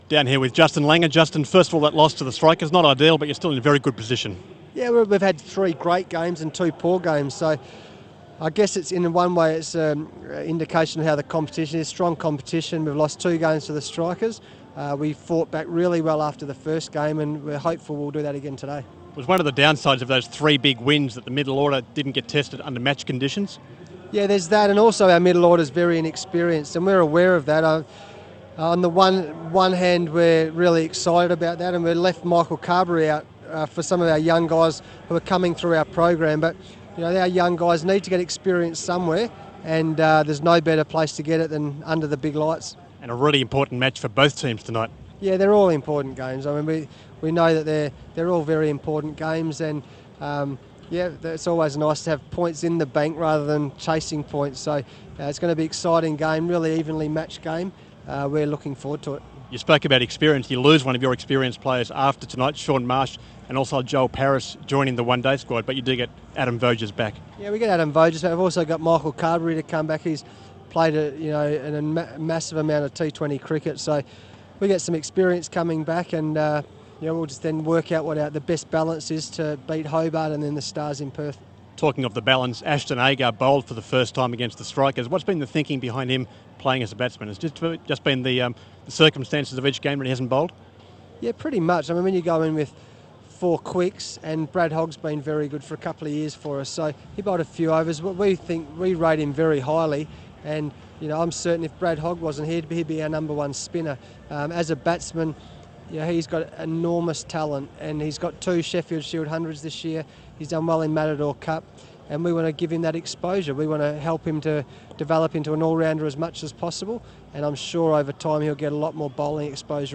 INTERVIEW: Perth Scorchers coach Justin Langer on bouncing back from defeat